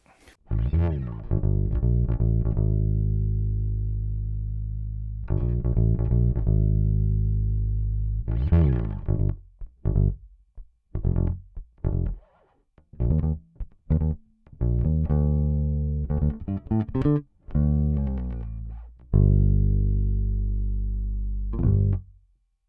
ベースラインでこの曲なーんだ
今月のベースラインはこちら！！